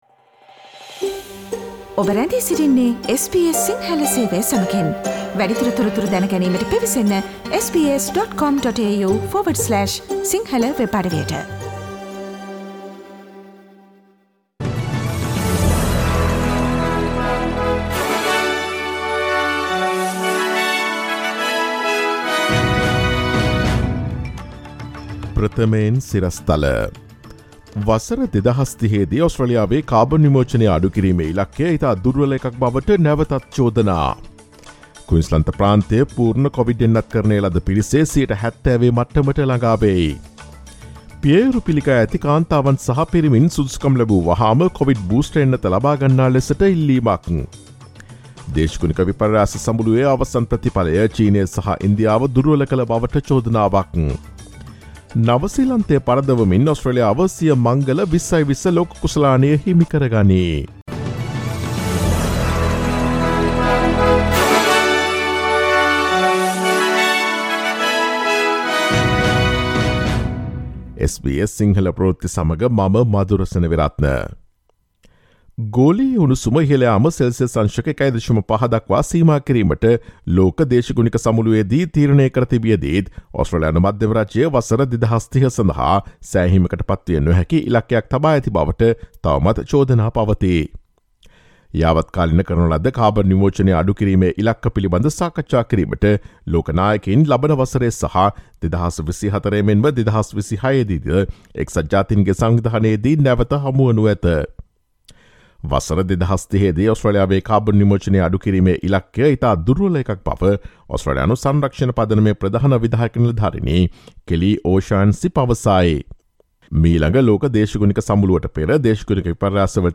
ඔස්ට්‍රේලියාවේ නවතම පුවත් මෙන්ම විදෙස් පුවත් සහ ක්‍රීඩා පුවත් රැගත් SBS සිංහල සේවයේ 2021 නොවැම්බර් 15 වන දා සඳුදා වැඩසටහනේ ප්‍රවෘත්ති ප්‍රකාශයට සවන් දීමට ඉහත ඡායාරූපය මත ඇති speaker සලකුණ මත click කරන්න.